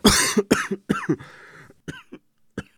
cough_0.ogg